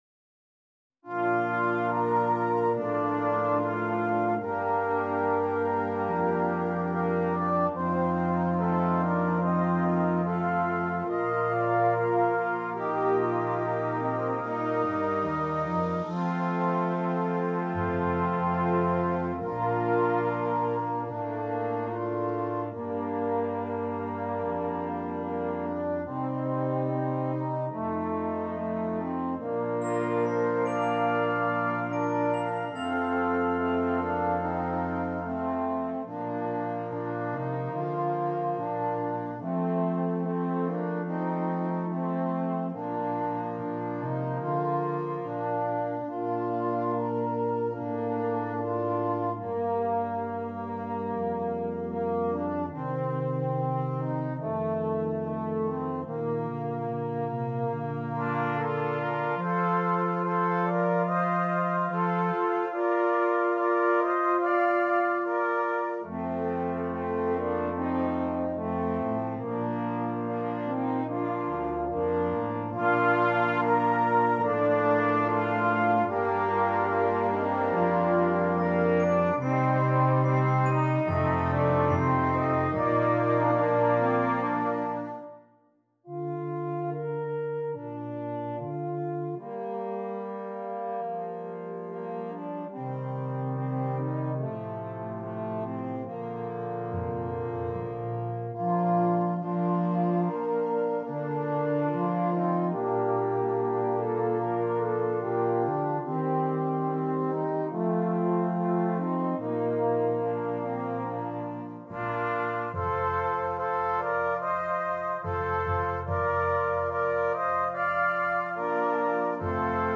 Brass Choir
Traditional Austrian Carol